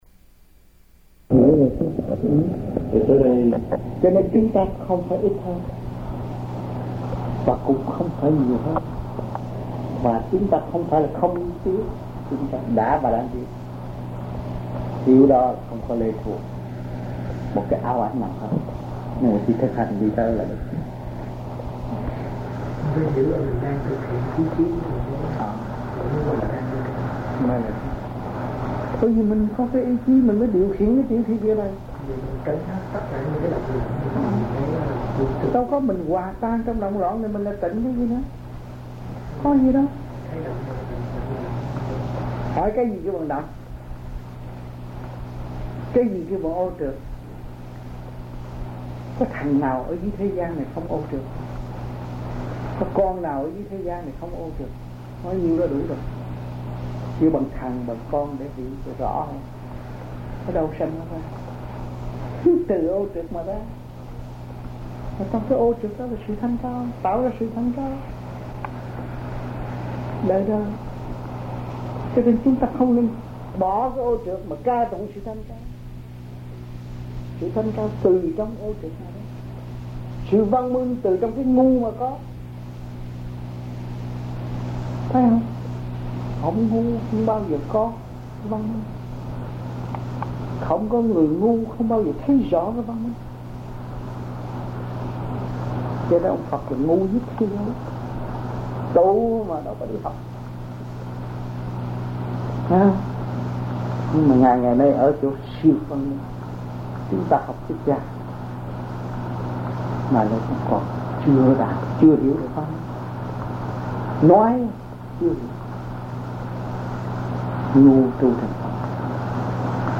France Trong dịp : Sinh hoạt thiền đường >> wide display >> Downloads